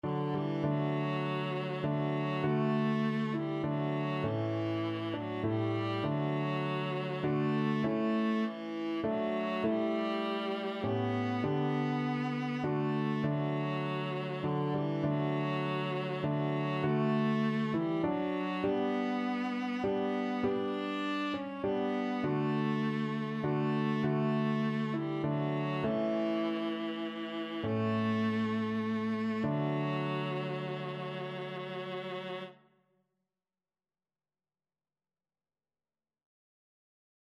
6/4 (View more 6/4 Music)
Classical (View more Classical Viola Music)